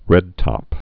(rĕdtŏp)